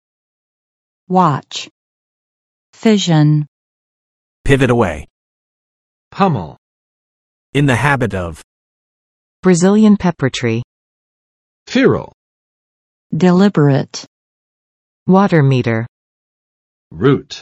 [wɑtʃ] n. 警戒，注意